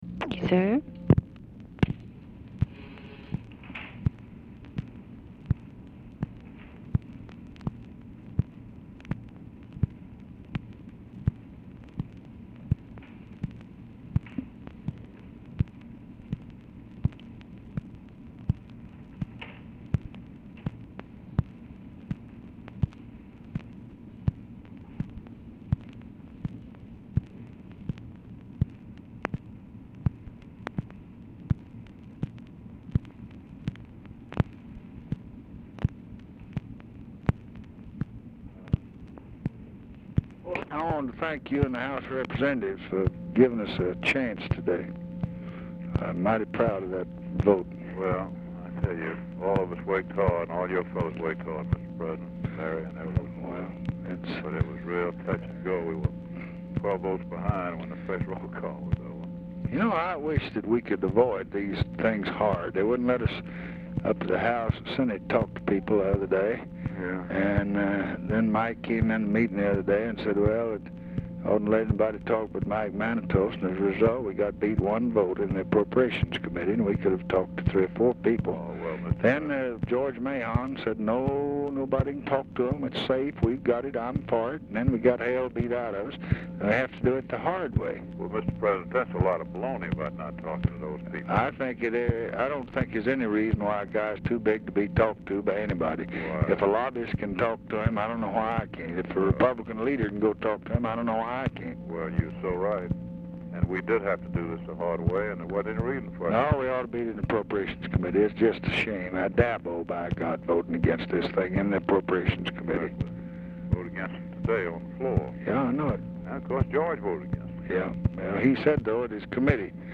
Telephone conversation # 10110, sound recording, LBJ and HALE BOGGS, 5/10/1966, 8:20PM | Discover LBJ
BOGGS ON HOLD 0:38
Format Dictation belt
Location Of Speaker 1 Oval Office or unknown location